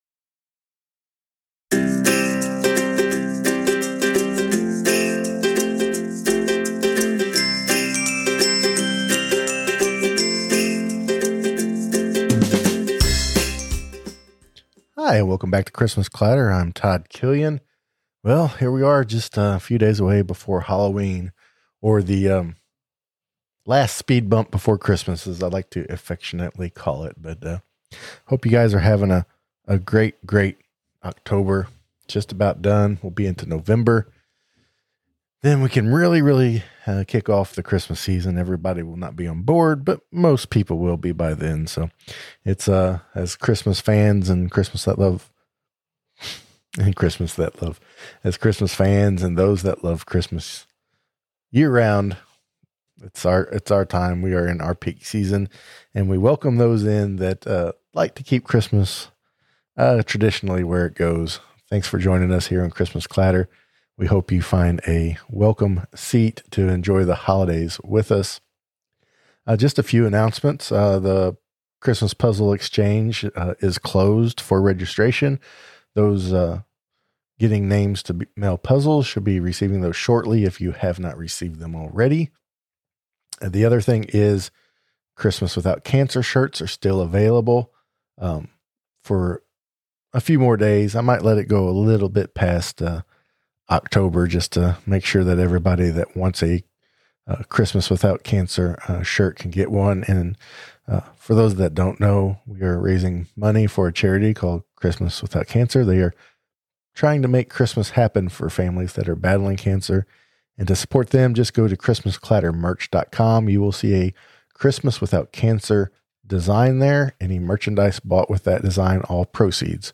Every Time A Bell Rings: The Zuzu Bailey Interview